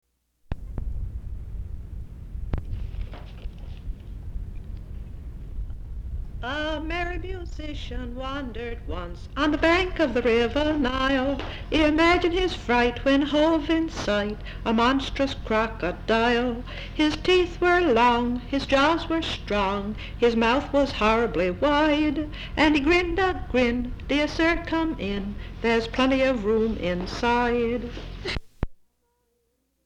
folksongs
Folk songs, English--Vermont
sound tape reel (analog)
East Dover, Vermont